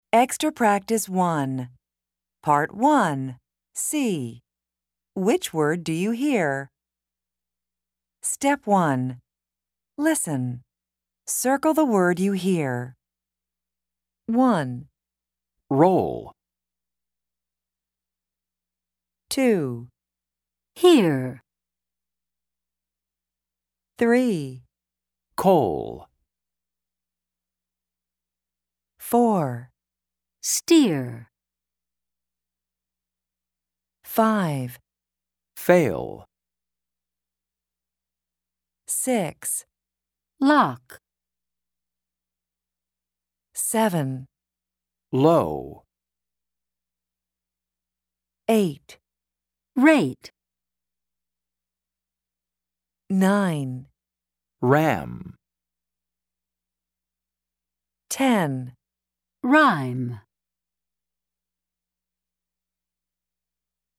Pronunciation and Listening Comprehension in North American English
American English